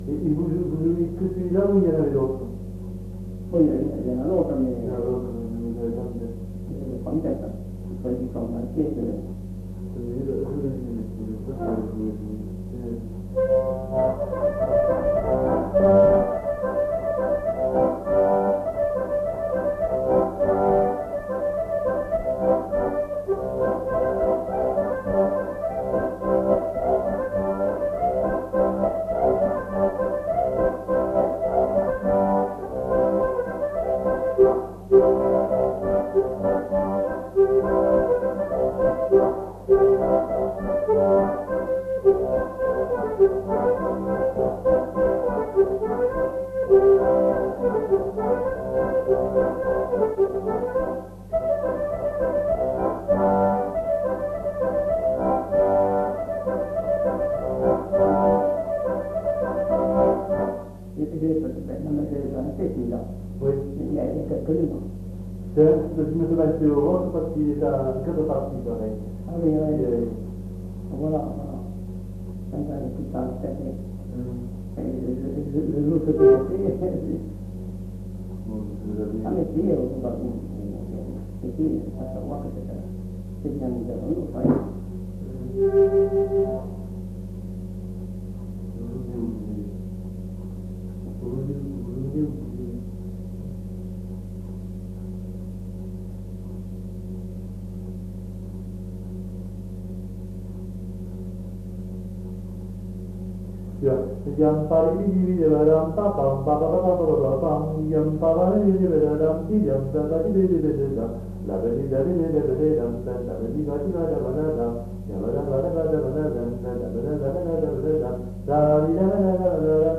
Aire culturelle : Marmandais gascon
Lieu : Escassefort
Genre : morceau instrumental
Instrument de musique : accordéon diatonique
Danse : congo
Notes consultables : Fredonne une mélodie de rondeau à la fin de la séquence.